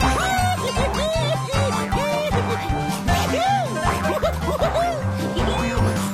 star_loop_3.ogg